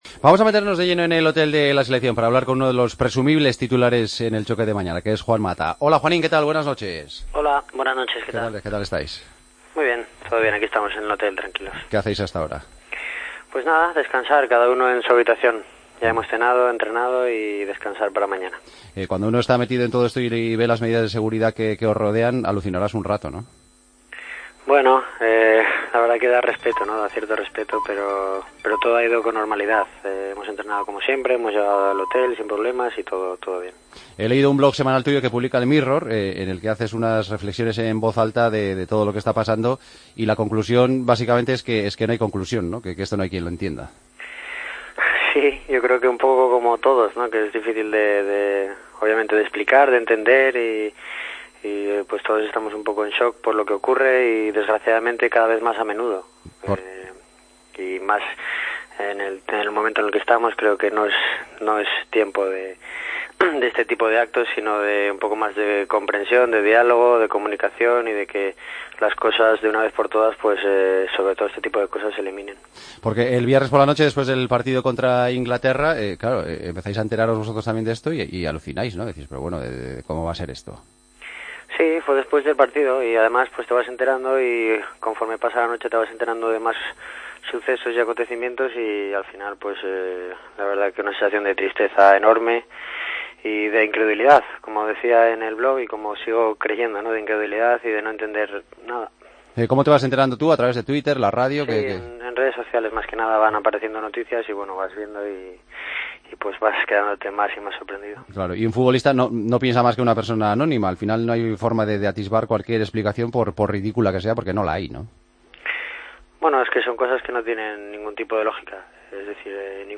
Hablamos con el jugador de la selección desde la concentración del equipo en Bélgica, donde España juega este martes un amistoso marcado por los atentados en París: "Ninguna idea tiene que estar por encima de una vida humana.